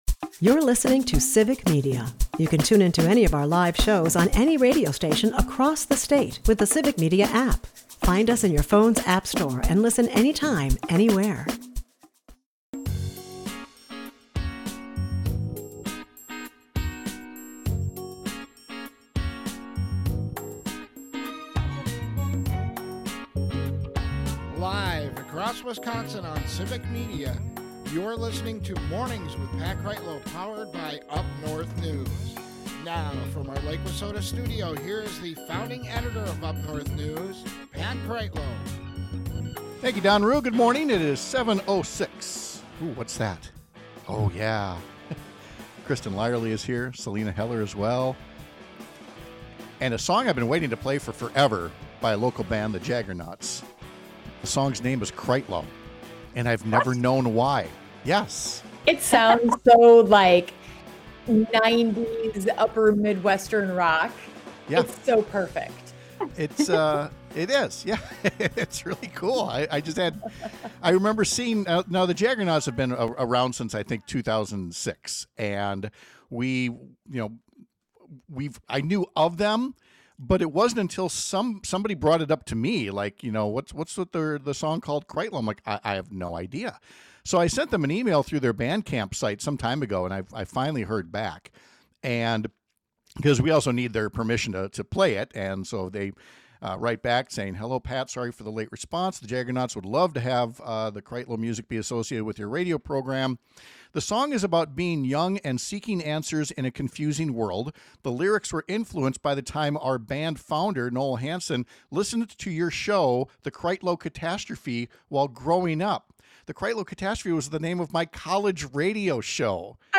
We’ll ask what you’d do if you were a legislator deciding which side to support. Mornings with Pat Kreitlow is powered by UpNorthNews, and it airs on several stations across the Civic Media radio network, Monday through Friday from 6-9 am.